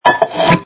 sfx_pick_up_glass01.wav